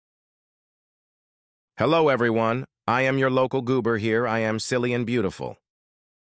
The text to speech was sound effects free download